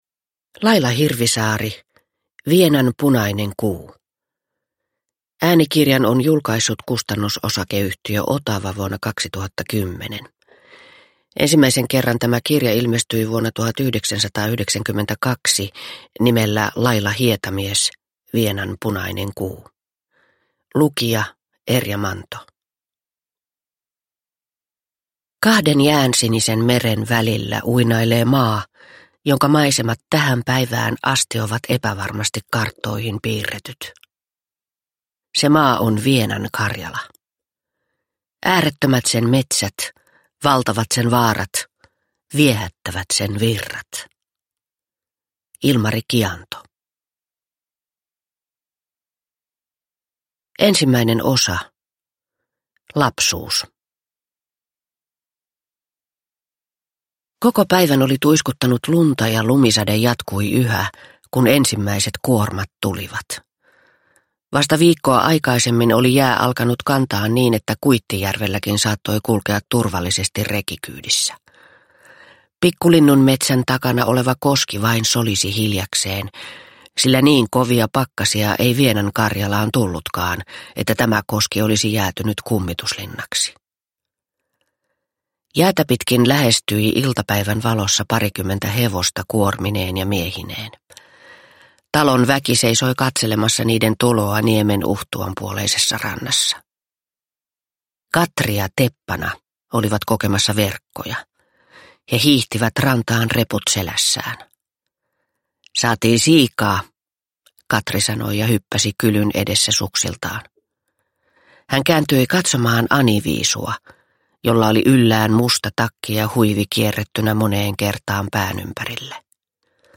Vienan punainen kuu – Ljudbok – Laddas ner